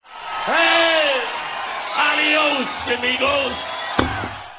FAçA DOWLOAD DE PEDAçOS DE UMAS MÚSICAS AO VIVO!!!
GUITARA
BAIXO
BATERA